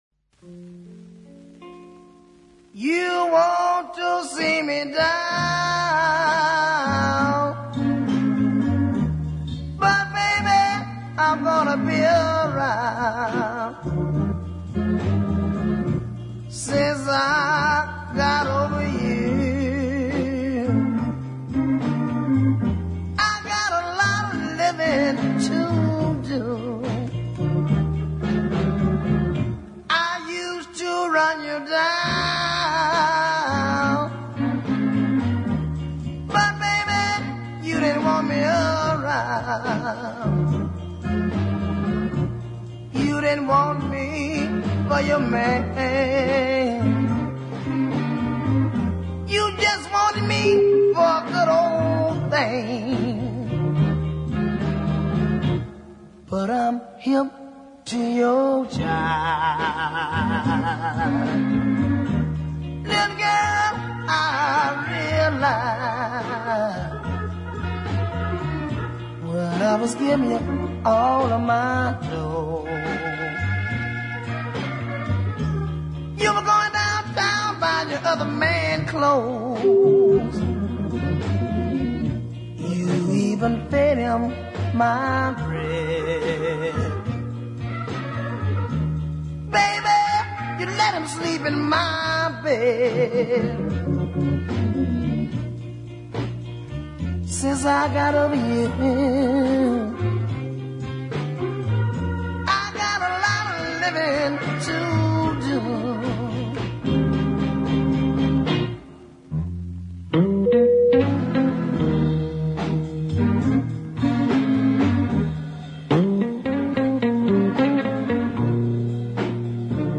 blues ballad
hoarse toned vocal sounded really fine